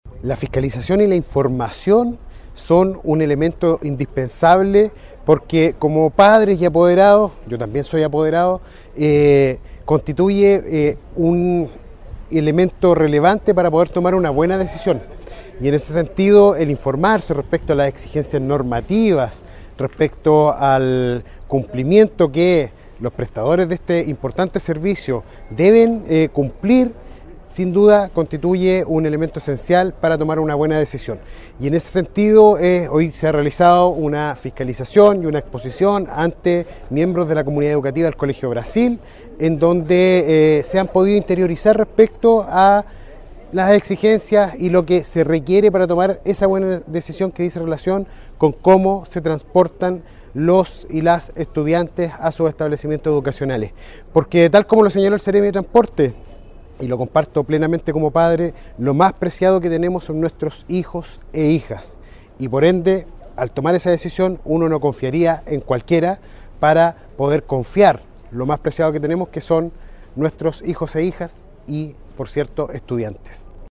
Por su parte, el seremi de Educación, Carlos Benedetti, destacó la importancia de chequear los servicios de traslado escolar antes de contratarlos.